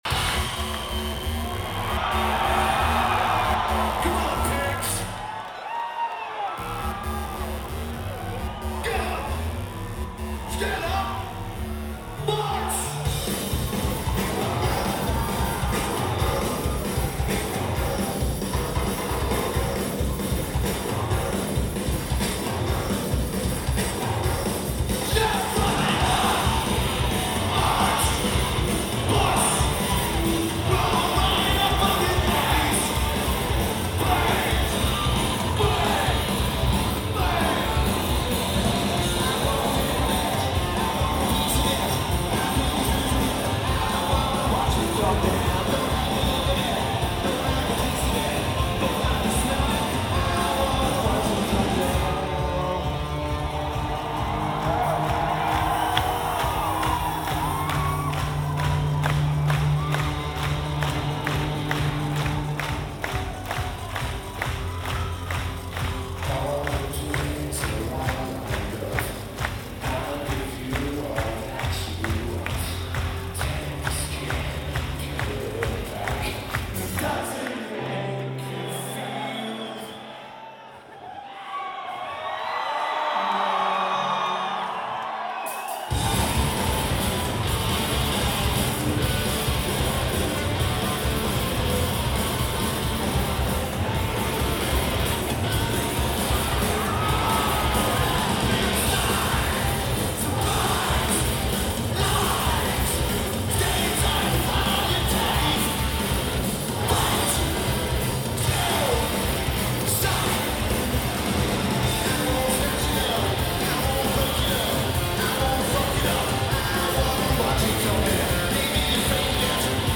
Metro Nightclub
Lineage: Audio - AUD (Sony ECM-DS70P + Iriver H320)